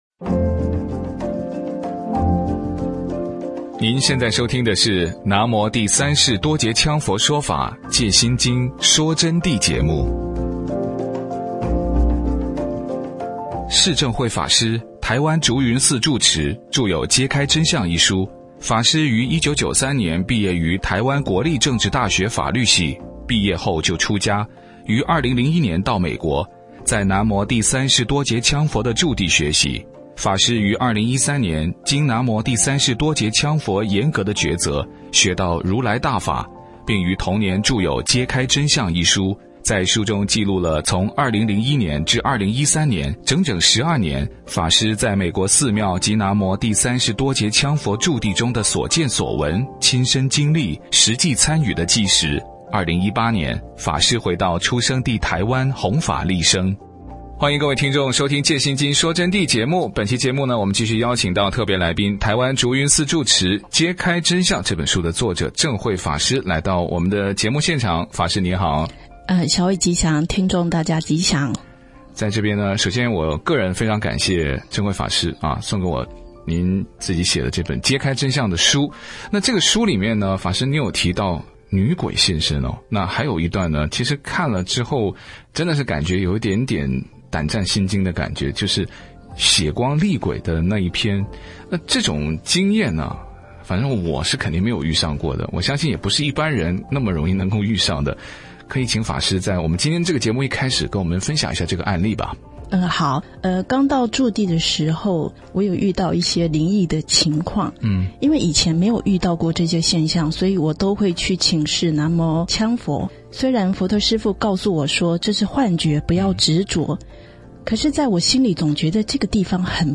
佛弟子访谈（四十八）